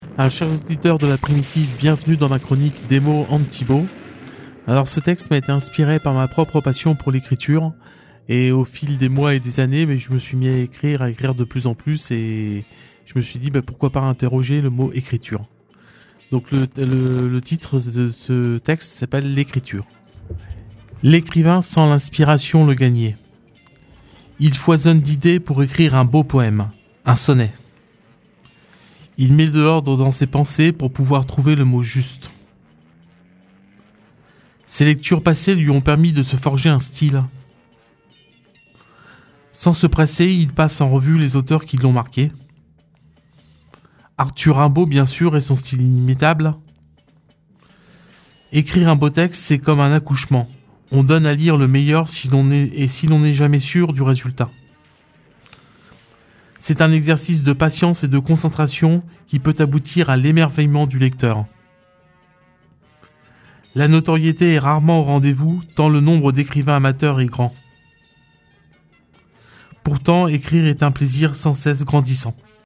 Chronique du 19 juin